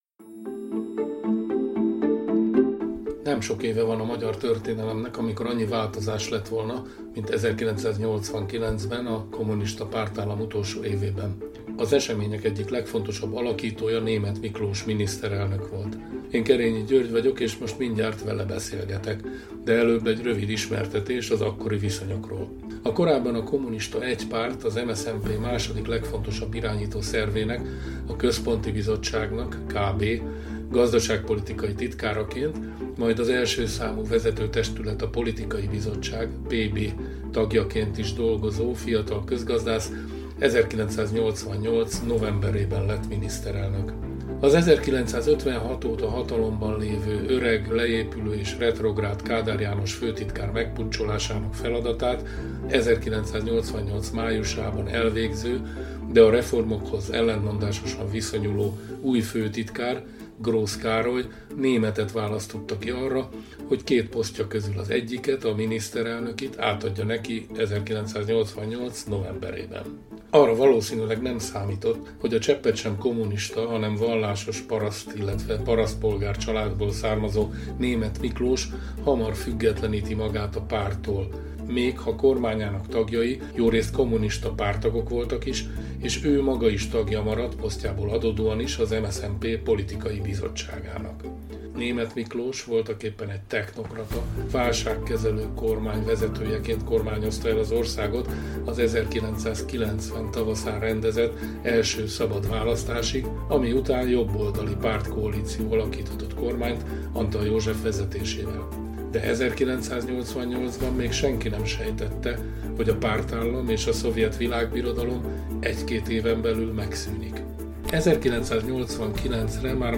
Az események egyik legfontosabb alakítója Németh Miklós miniszterelnök volt. Kétrészes interjúnkban emlékezik vissza az átmenet néhány aspektusára.